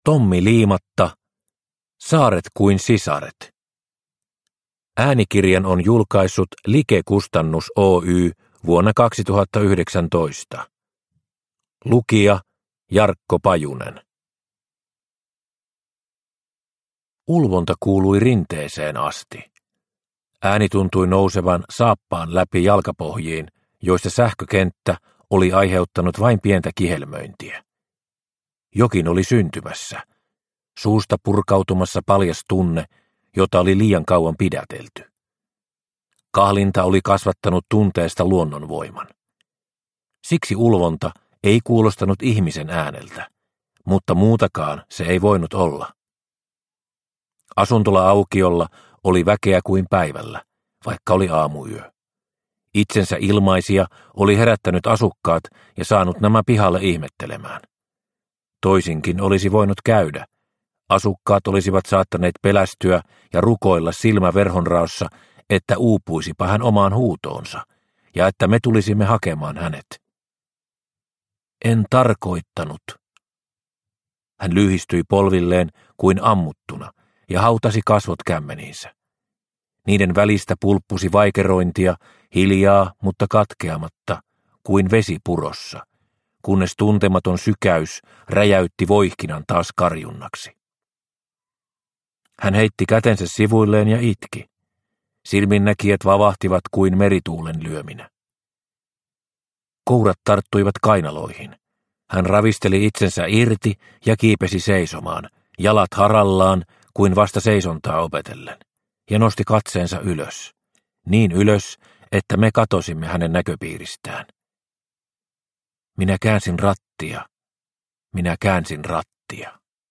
Saaret kuin sisaret – Ljudbok – Laddas ner